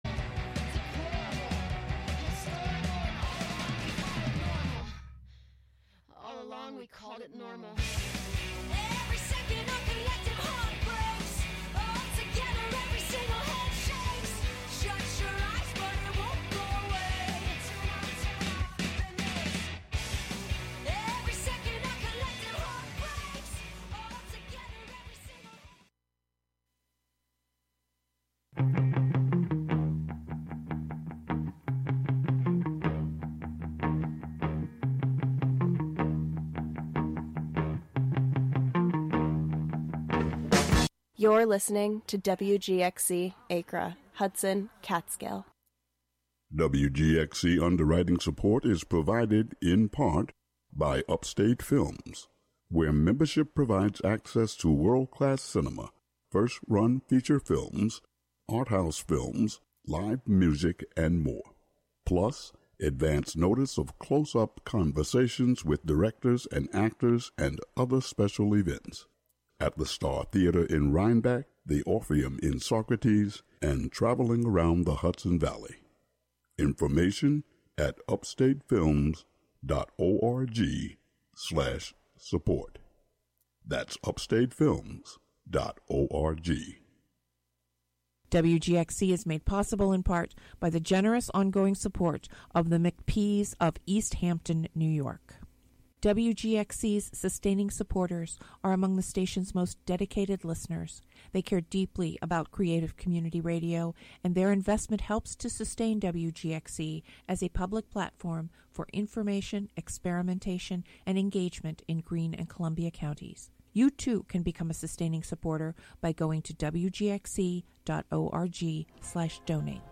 Interstice is an invitation to tuck into the corners of living we are often rewarded to avoid–silence, play, shadows, absurdity, loss, and slowness, through music, field recording, archival audio, and conversation.